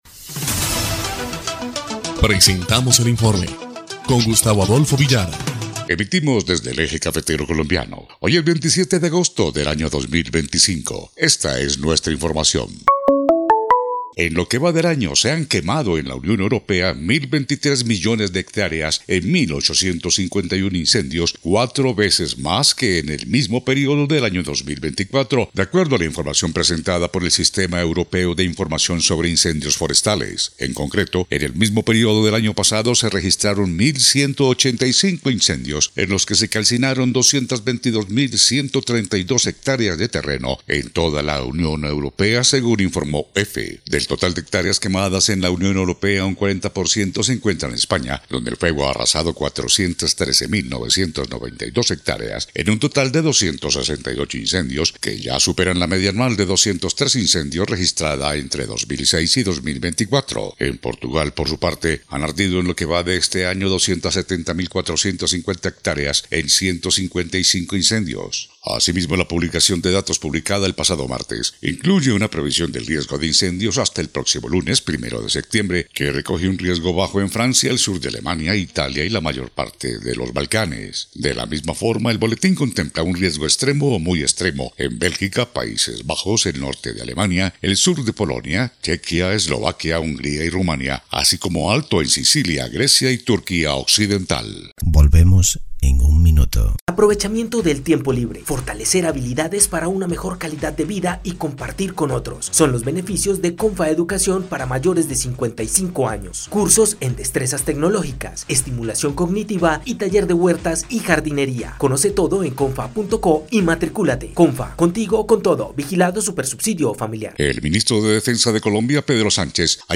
EL INFORME 2° Clip de Noticias del 27 de agosto de 2025